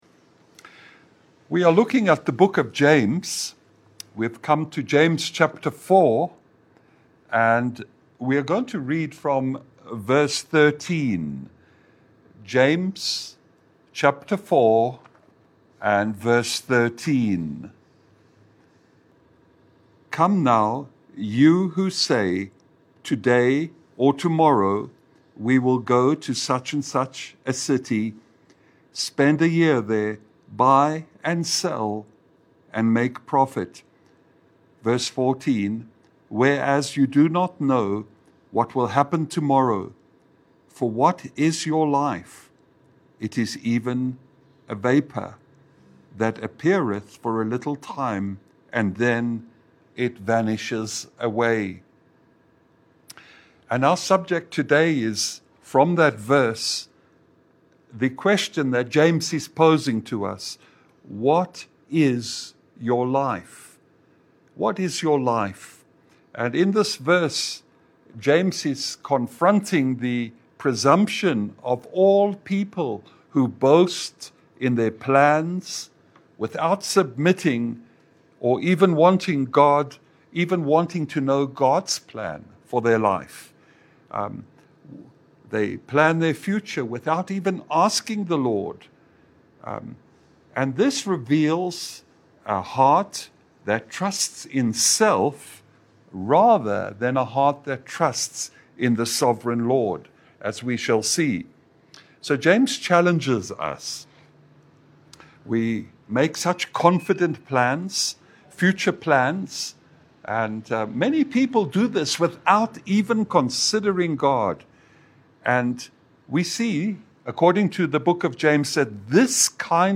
A Clear Message from James 4 preaching at Nanyuki mid week Bible study .
James 4:14 Service Type: Lunch hour Bible Study « Hallow God’s name in everyday life Treasures on earth and treasures in Heaven.